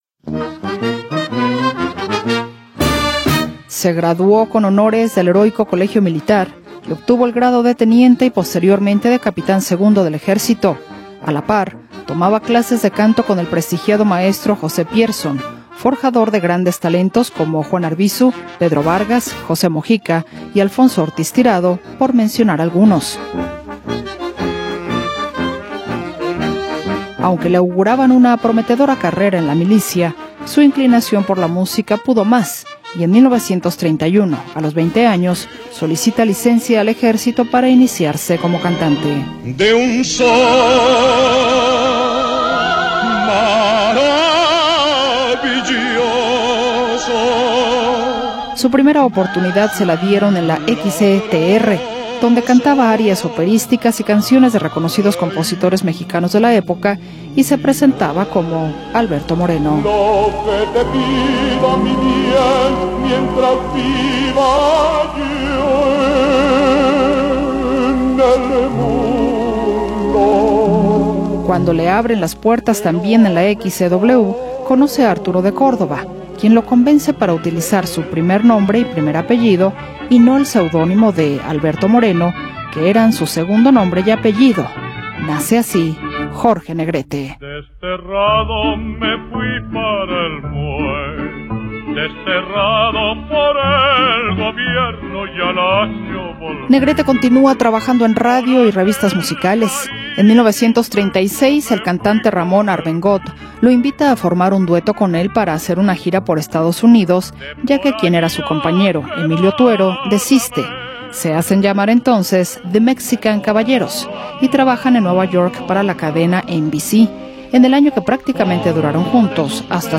pero alcanzó la fama interpretando música ranchera